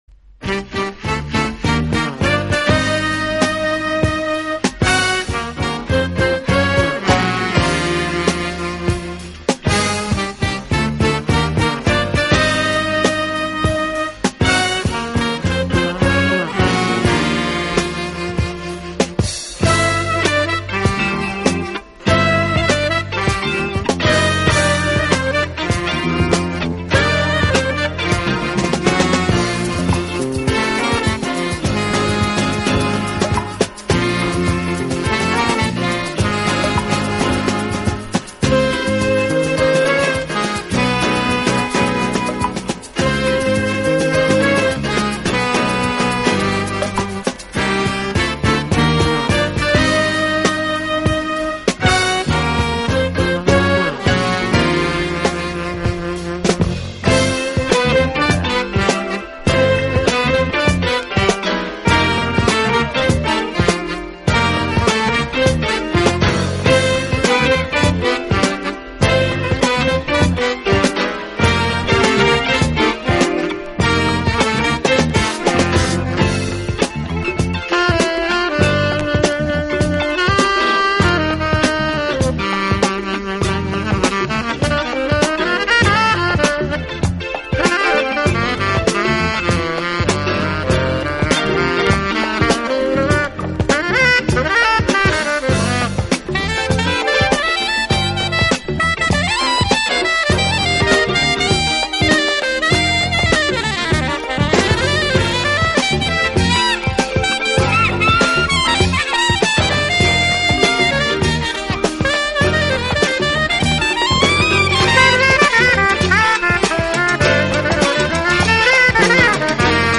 【Smooth Jazz】